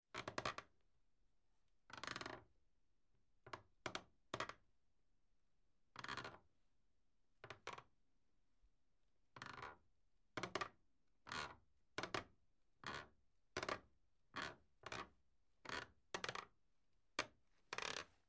Скрип половиц из дерева